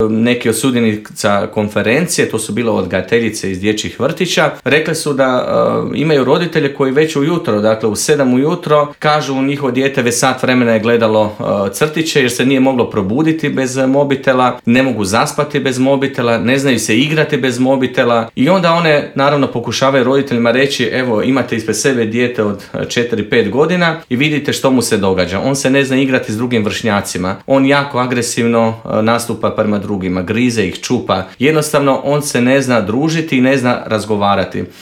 O svemu tome u Intervjuu Media servisa